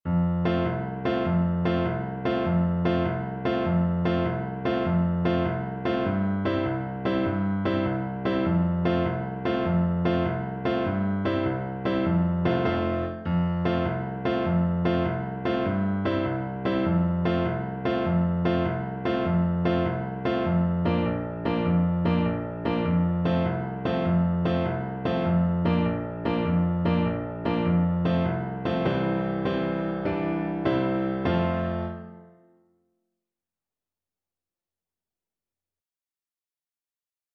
Allegro .=c.100 (View more music marked Allegro)
6/8 (View more 6/8 Music)